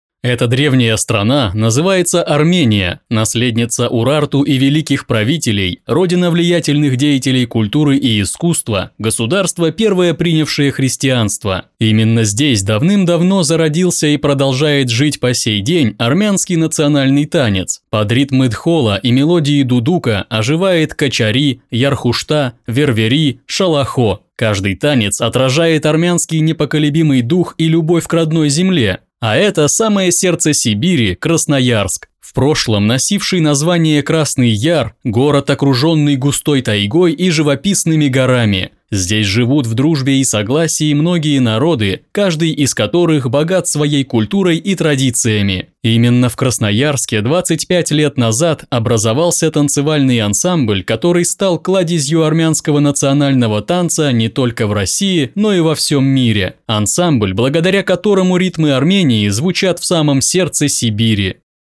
Муж, Закадровый текст/Средний
Focusrite 2i2 2nd gen., Audio-Technica AT2035